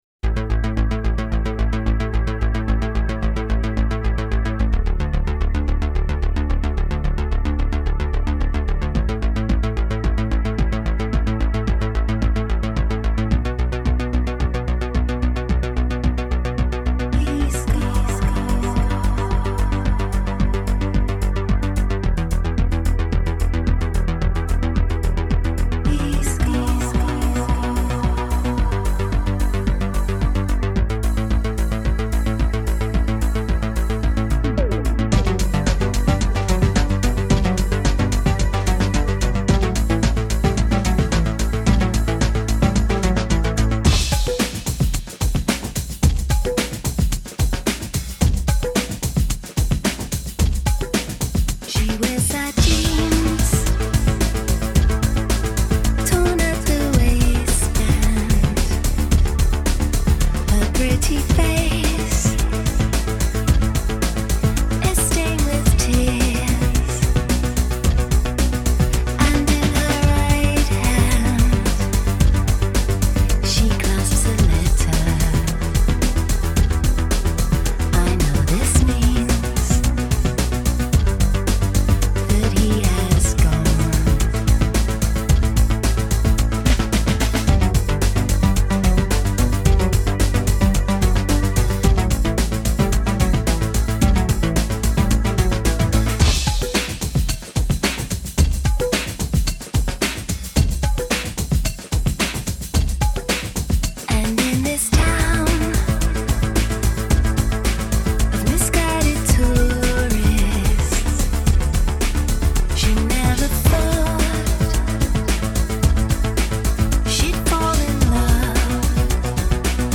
motorik thunk